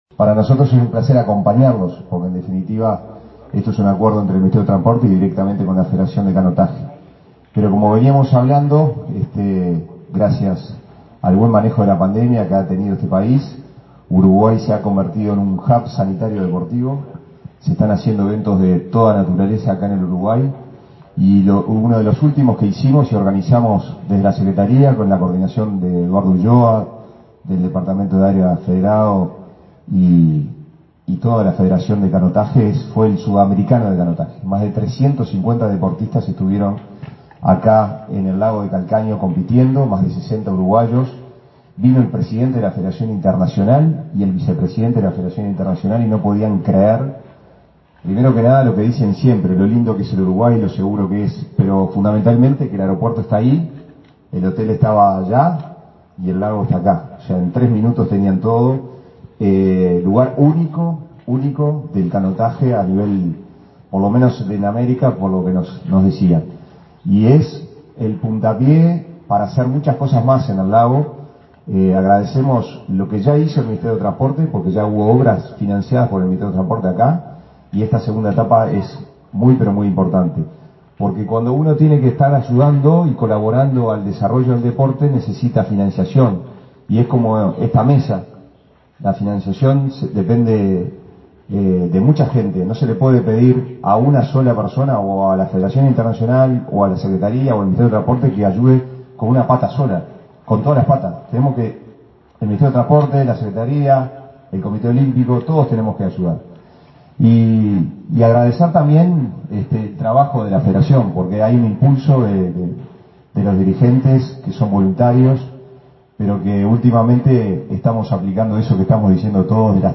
Palabras de autoridades de la Secretaría del Deporte y del Ministerio de Transporte
El subsecretario del Deporte, Pablo Ferrari, y el titular de Transporte, José Luis Falero, participaron este jueves 23 en el acto de firma de un